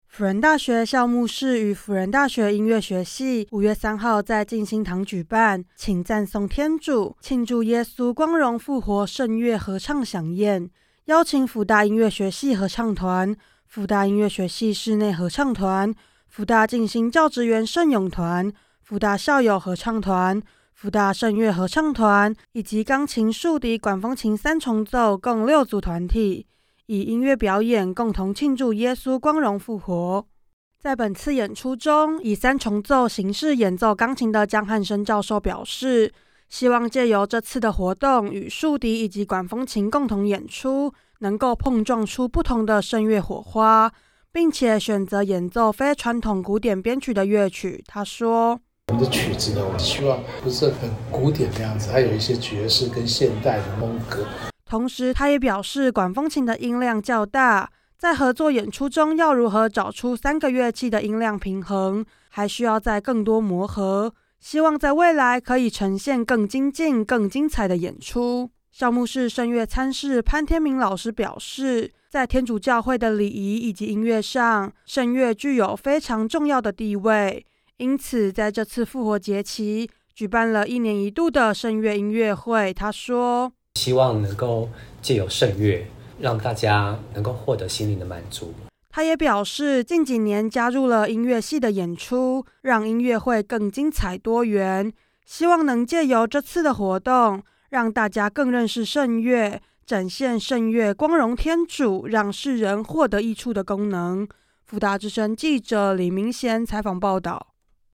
1122藝－慶祝耶穌光榮復活 淨心堂合唱饗宴